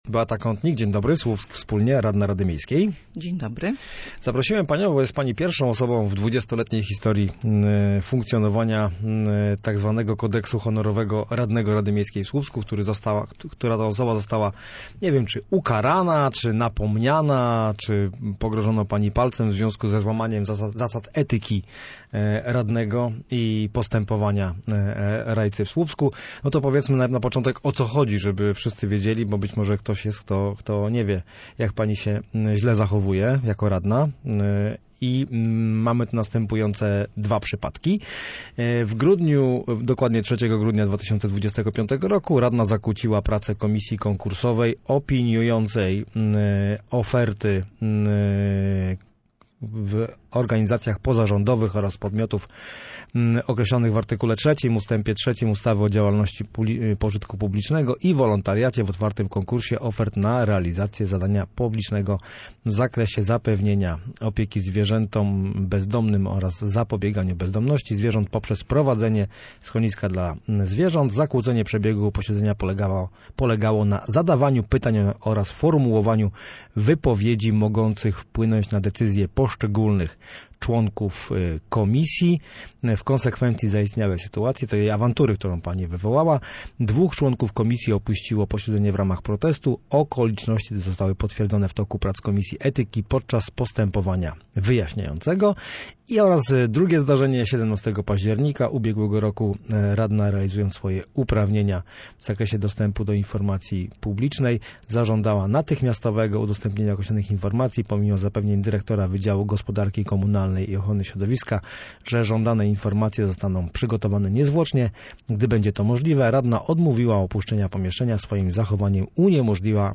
Radna Beata Kątnik była gościem Studia Słupsk.